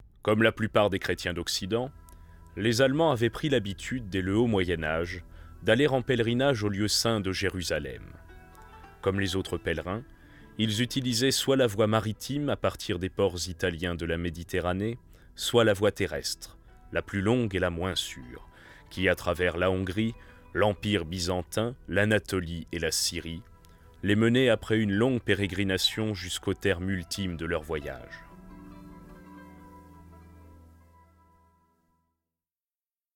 Lecture ouvrage historique
35 - 55 ans - Basse